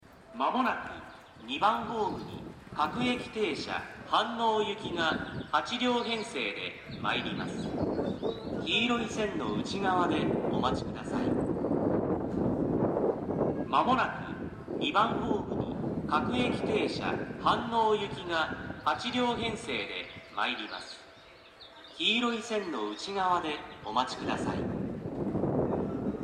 この駅では接近放送が使用されています。
接近放送各駅停車　飯能行き接近放送です。